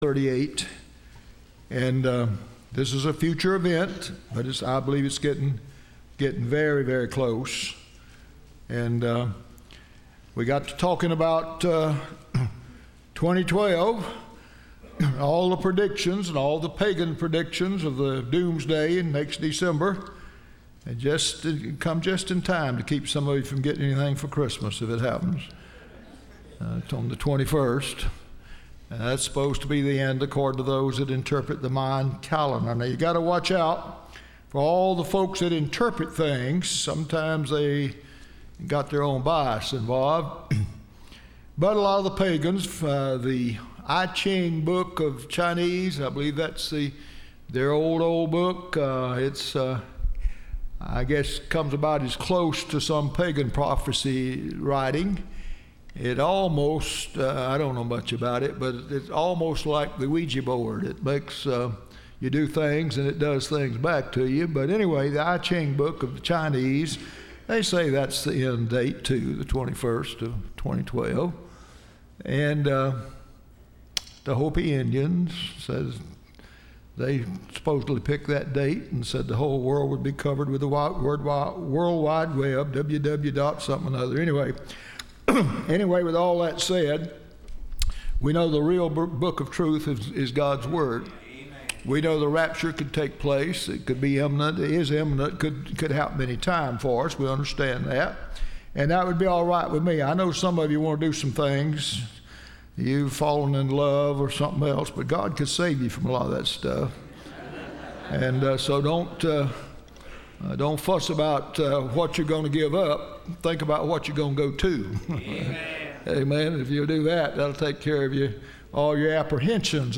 Service Type: Wednesday